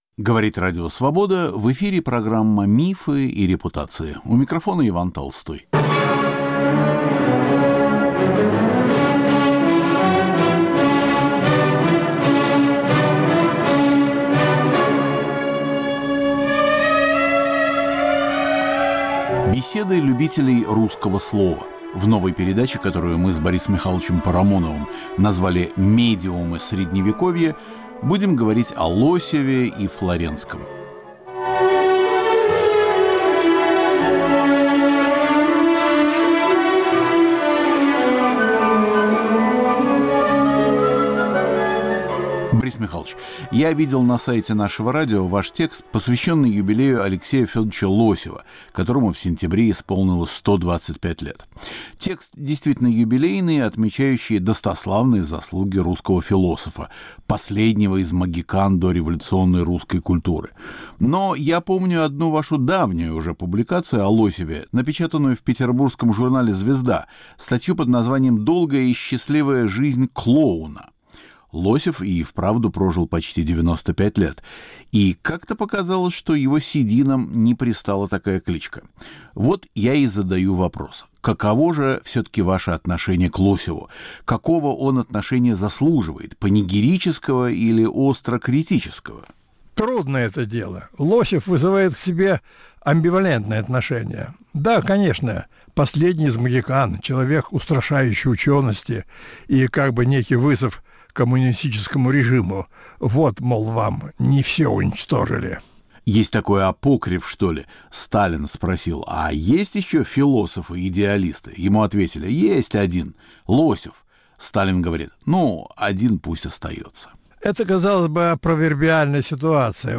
Медиумы средневековья - новая Беседа любителей русского слова. Борис Парамонов в разговоре с Иваном Толстым обсуждают двух философов ХХ века, Алексея Лосева и Павла Флоренского.